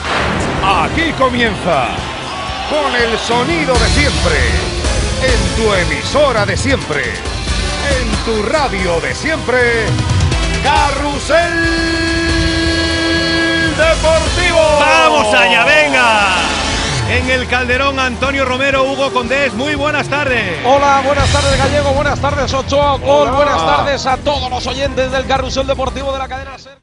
Inici del programa i connexió amb l'Estadio Calderón de Madrid
Esportiu